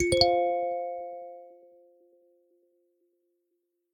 session-ios / Signal / AudioFiles / messageReceivedSounds / chord.aifc
chord.aifc